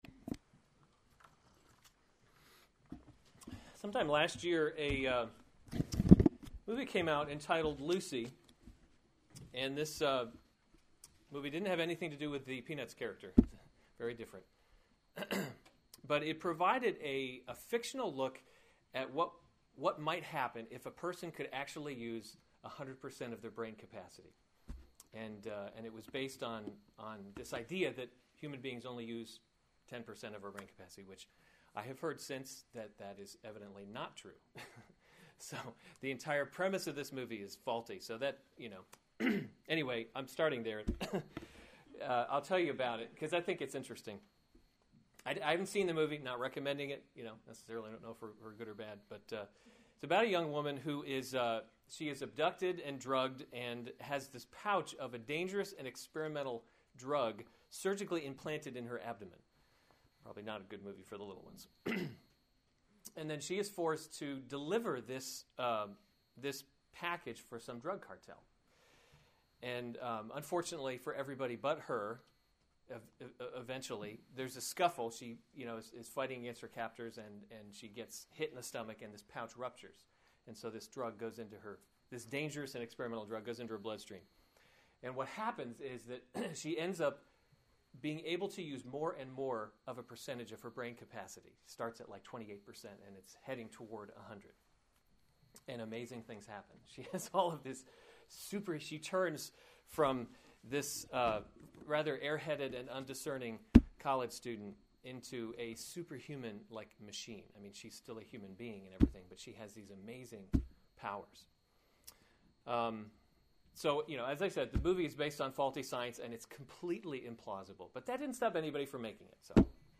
January 31, 2015 Romans – God’s Glory in Salvation series Weekly Sunday Service Save/Download this sermon Romans 11:33-36 Other sermons from Romans 33 Oh, the depth of the riches and wisdom […]